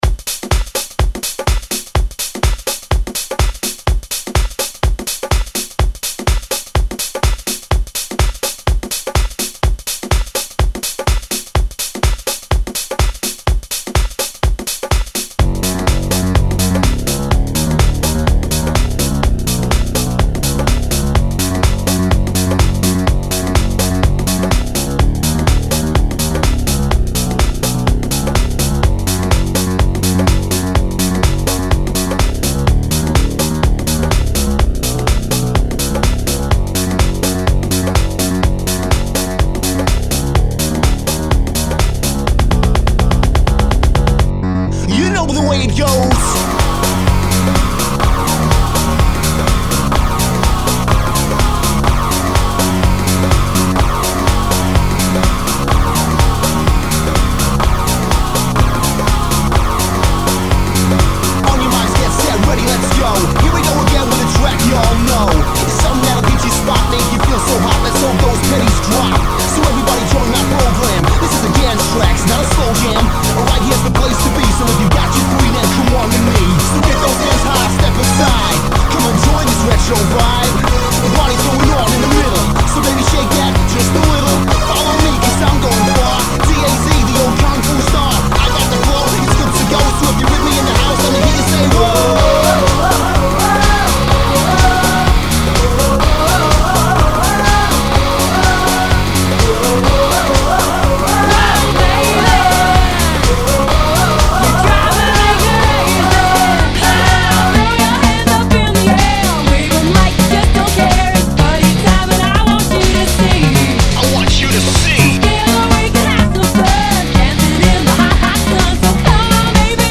dance/electronic
a mashup